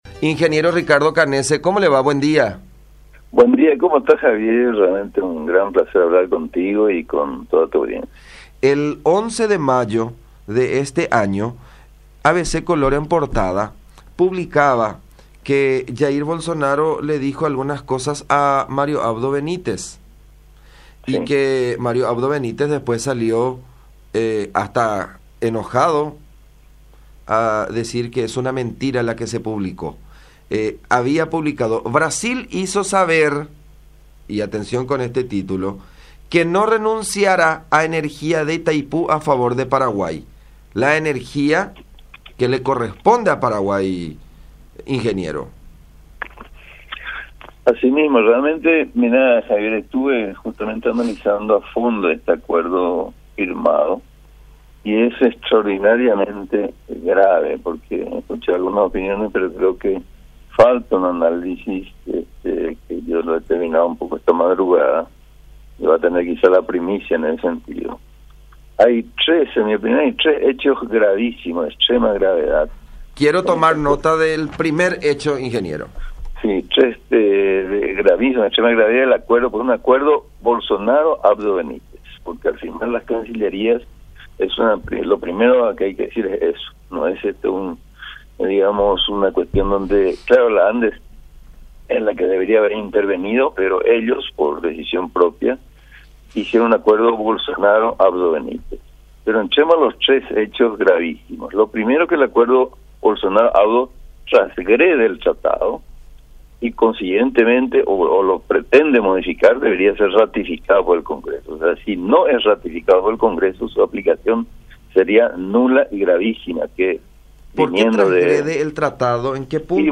Canese comentó en diálogo con La Unión que el Acta Bilateral firmado entre ANDE y Eletrobras en torno a la Itaipú Binacional, apoyado por los presidentes de Brasil y Paraguay, Jair Bolsonaro y Mario Abdo Benítez, respectivamente, provocaría para nuestro país una pérdida de US$ 2.190 millones.